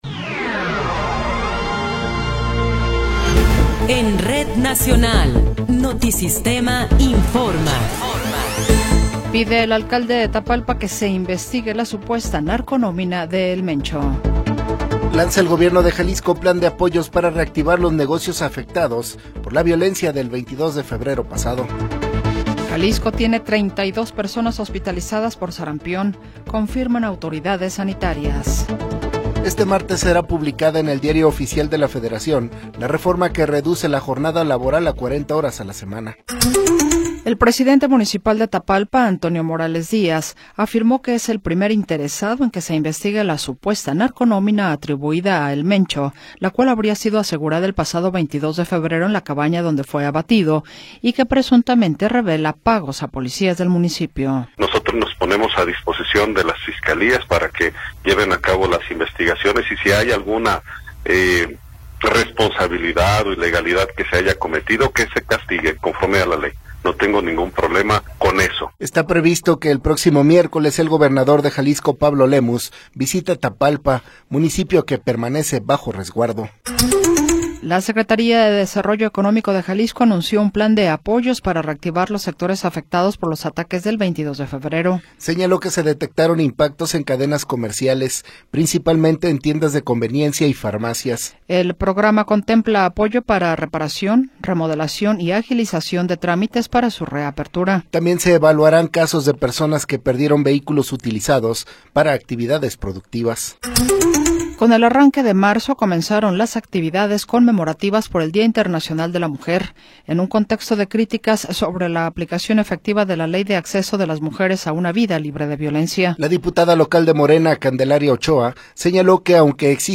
Noticiero 20 hrs. – 2 de Marzo de 2026
Resumen informativo Notisistema, la mejor y más completa información cada hora en la hora.